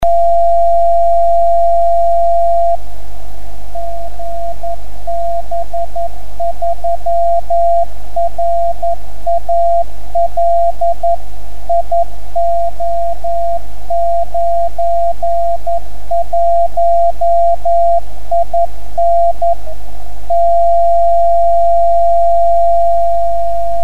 10m Baken
Die hier aufgeführten Stationen wurden selbst empfangen.